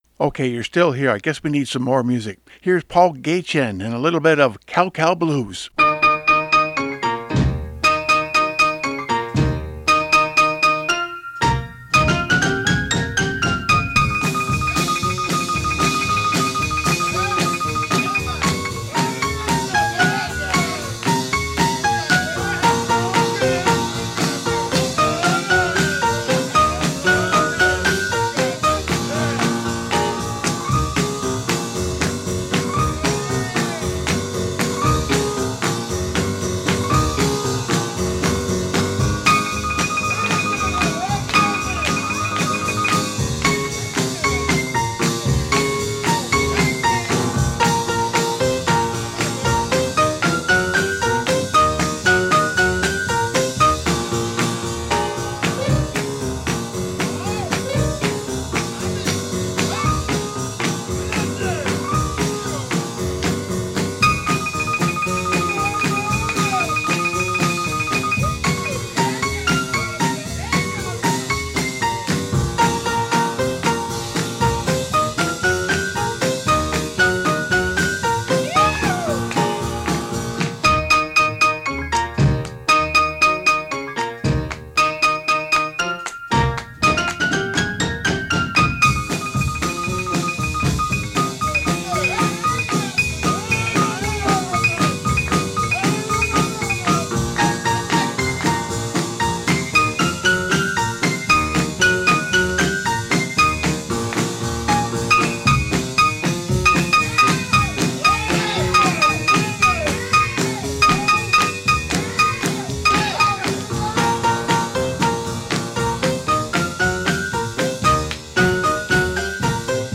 Closing is at about the 56 minute mark, plenty of instrumental fill after that which can be cut or faded at any time that suits.
320kbps Stereo